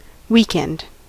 Ääntäminen
IPA: /wi.kɛnd/